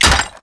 wrench_hit_tile1.wav